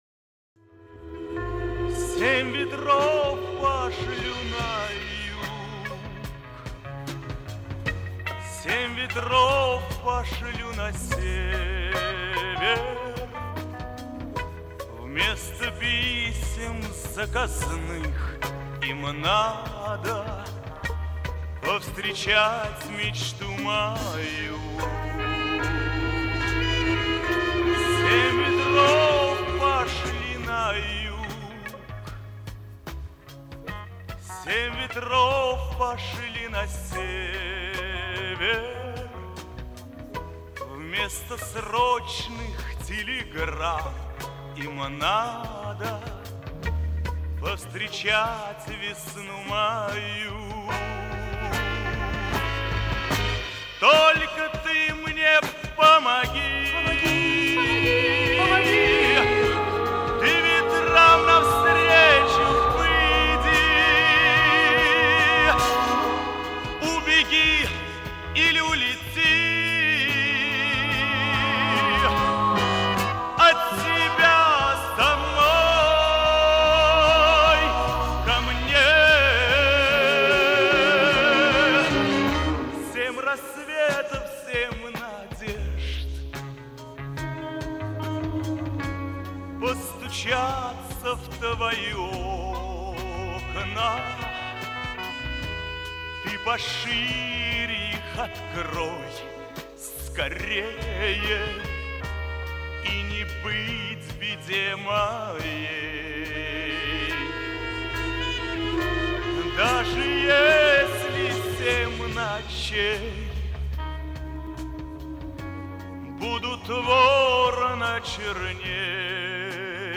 Записи Ленинградского радио.